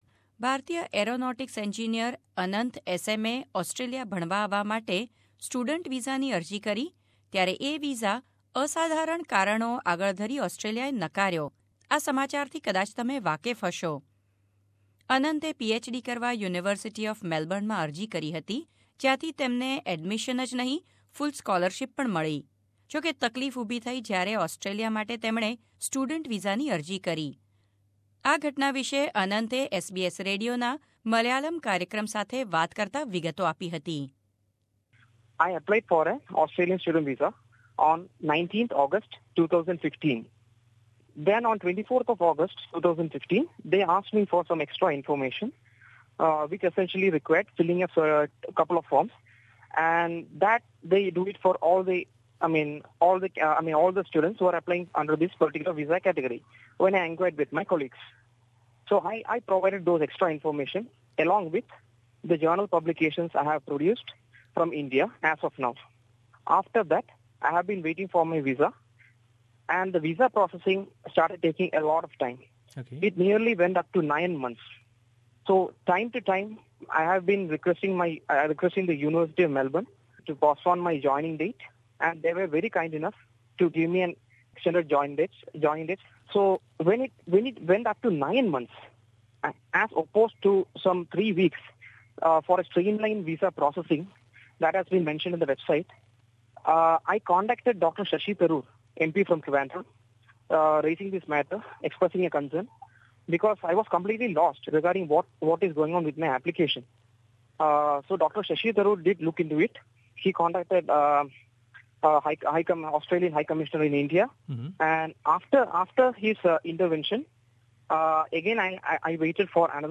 Indian IIT graduate talks to SBS after being denied student visa